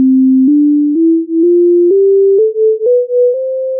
The following WAV file has two "players" playing a C major scale. One of the players is using the Just Scale, the other the Equal Tempered scale. Both start on exactly the same pitch. See if you can hear the notes where the pitches are different by listening for the beats.
Major scales in different temperaments